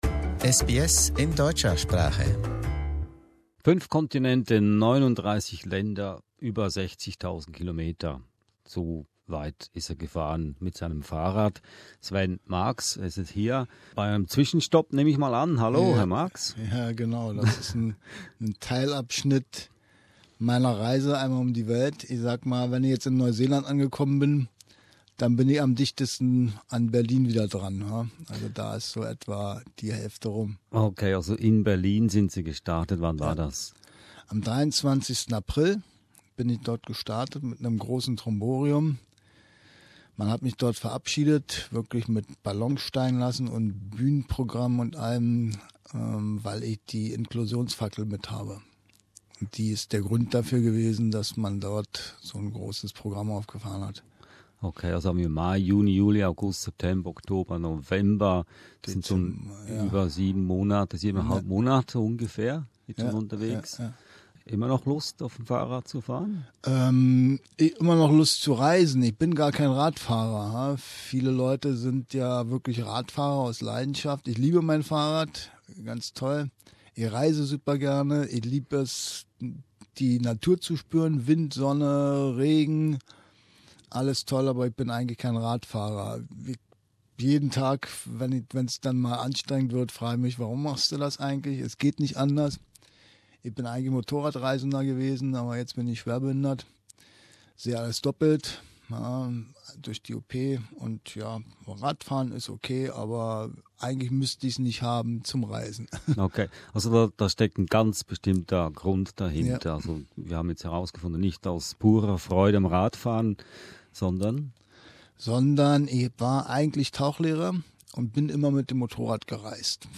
Er machte einen Zwischenhalt in den SBS Studios in Melbourne, um seine faszinierende Geschichte zu erzählen.